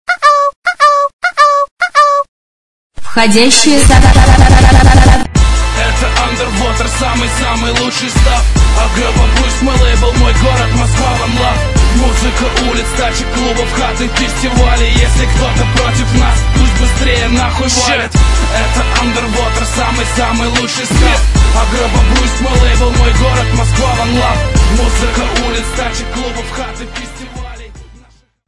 мужской вокал
русский рэп